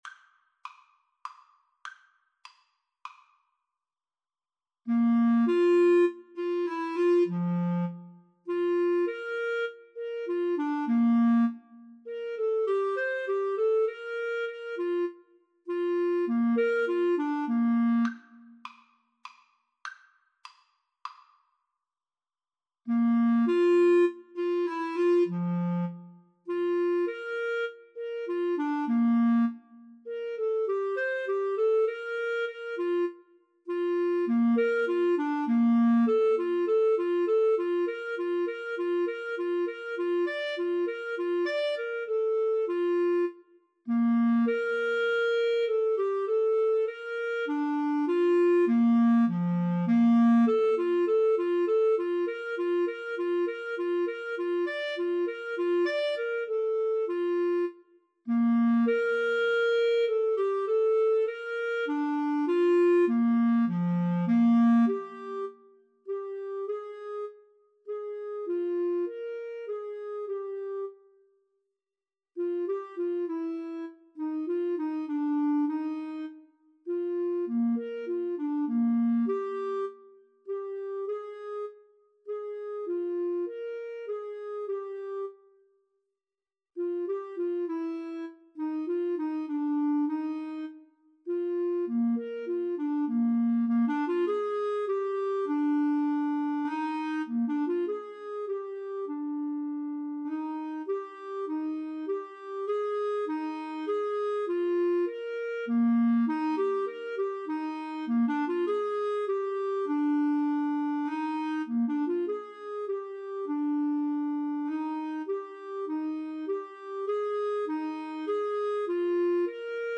3/4 (View more 3/4 Music)
Menuetto
Classical (View more Classical Flute-Clarinet Duet Music)